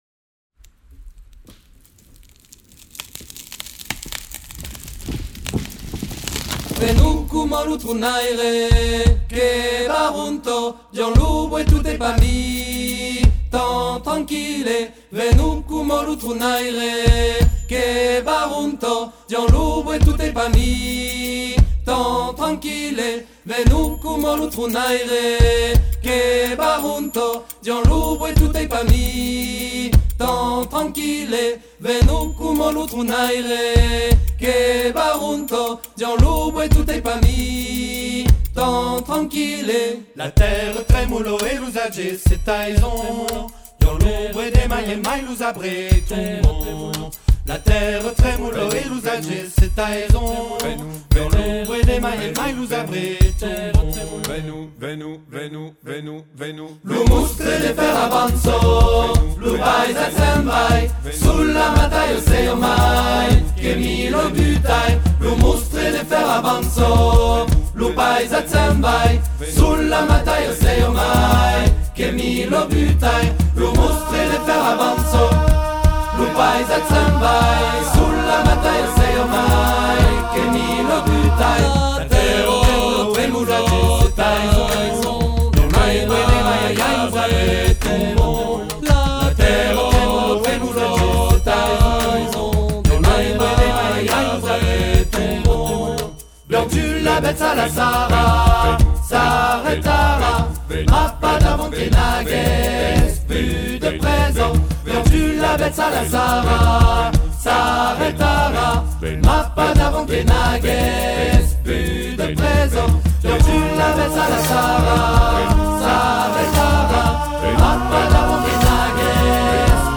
Interview aujourd’hui